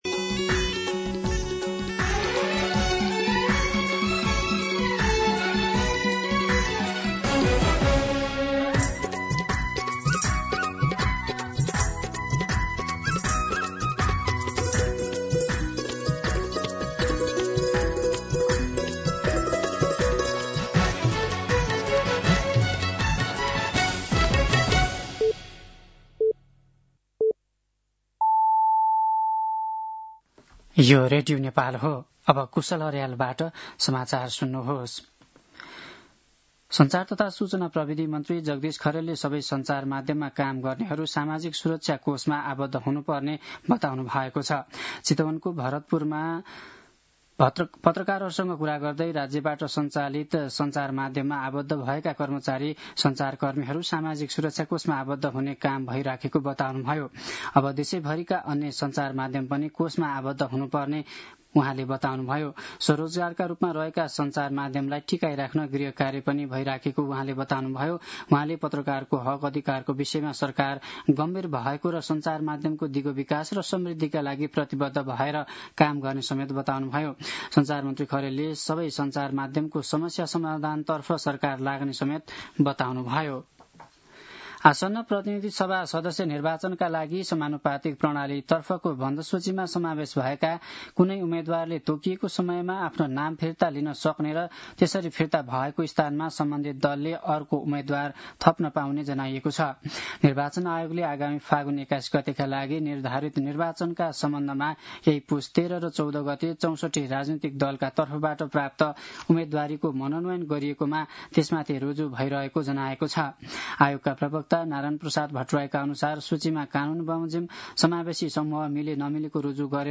दिउँसो १ बजेको नेपाली समाचार : १९ पुष , २०८२
1-pm-Nepali-News-1.mp3